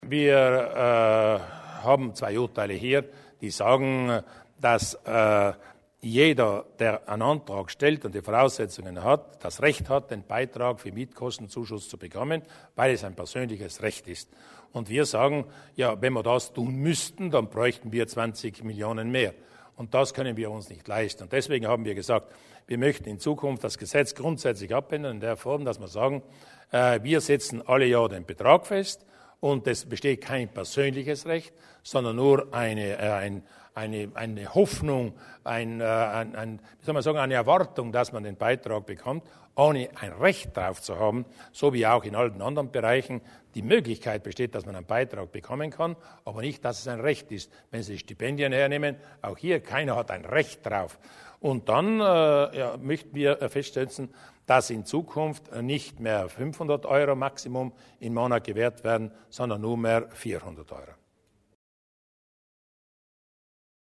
Landeshauptmann Durnwalder zu den neuen Vorschlägen für das Wohngeld